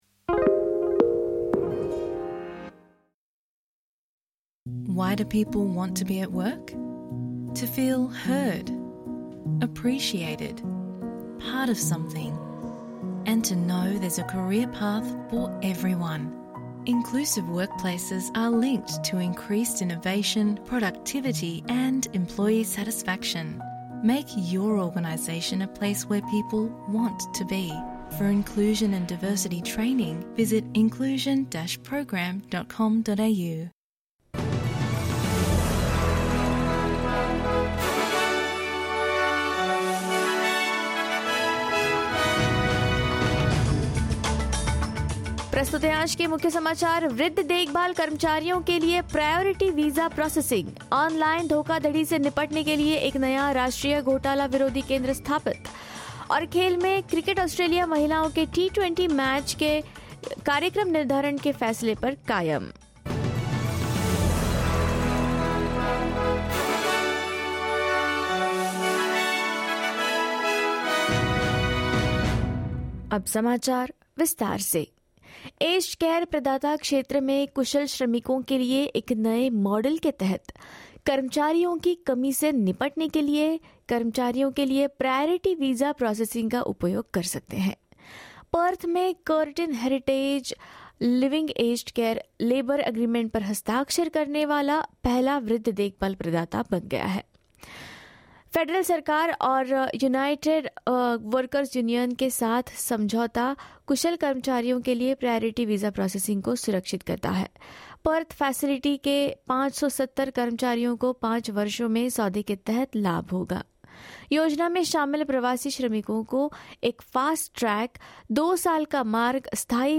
In this latest Hindi bulletin: Aged care workers to get priority visa processing; New National Anti-Scams Centre set to resist online fraud; In sports, Cricket Australia supports its scheduling choice of women's T20 match and more.